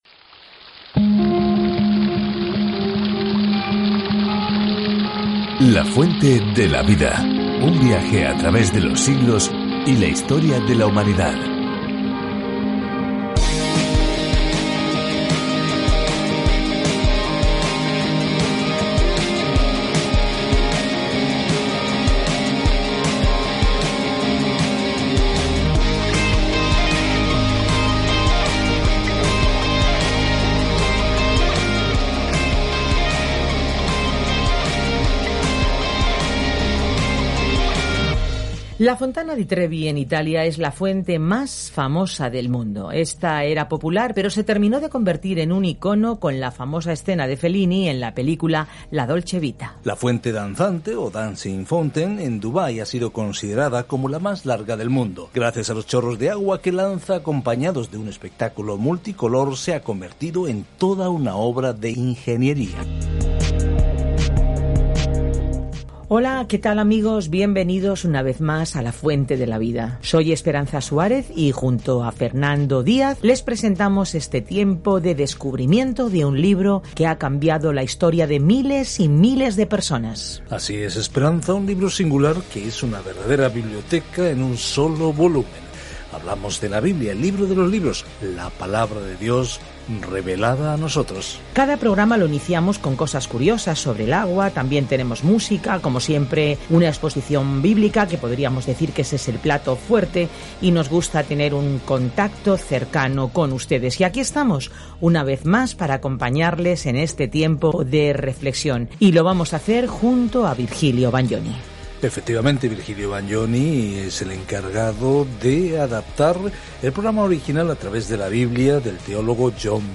Escritura MARCOS 5:1-43 Día 6 Iniciar plan Día 8 Acerca de este Plan El evangelio más breve de Marcos describe el ministerio terrenal de Jesucristo como el Siervo sufriente y el Hijo del Hombre. Viaja diariamente a través de Marcos mientras escuchas el estudio de audio y lees versículos seleccionados de la palabra de Dios.